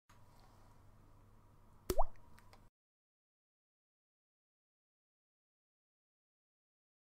دانلود آهنگ چکه چکه قطره آب 4 از افکت صوتی طبیعت و محیط
جلوه های صوتی
دانلود صدای چکه چکه قطره آب 4 از ساعد نیوز با لینک مستقیم و کیفیت بالا